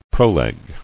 [pro·leg]